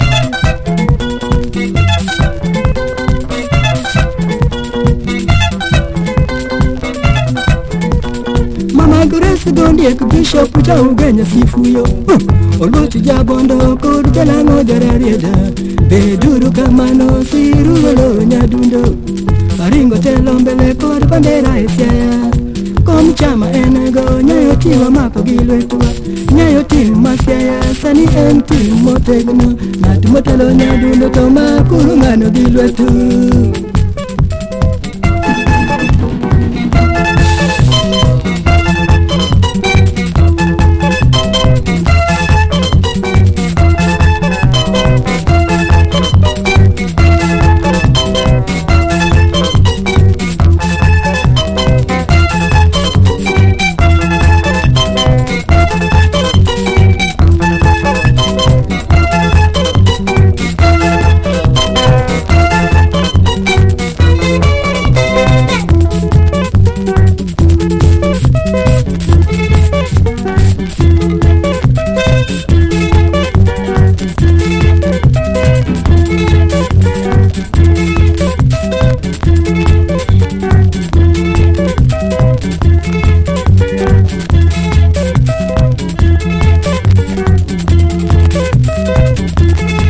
FRENCH / YE YE / OLDIES
キュートな魅力爆発のフレンチ・ポップ！
雄大なコーラスに悩ましげなヴォーカルの
フリップバック/コーティング・ジャケット/フランスORIGINAL盤MONO！